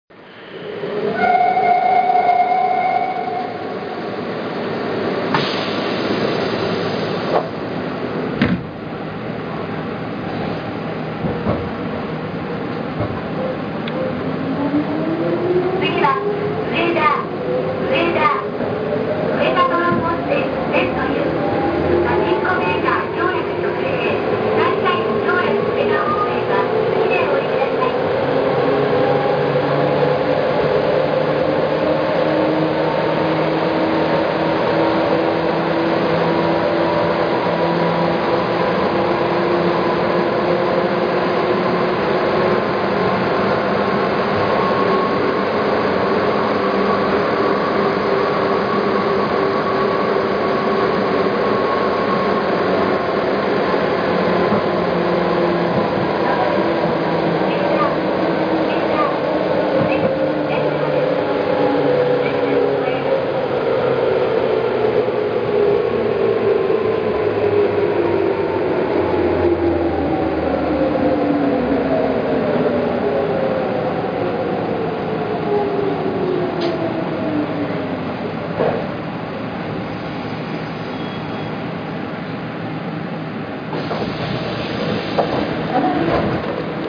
・100系（抵抗制御車）走行音
【名古屋市営鶴舞線】原→植田（1分27秒：681KB）
抵抗制御の車両に関してはいかにもなそれっぽい音。
いかに車内が豪華であろうが、モーターの古さのごまかしまではききません。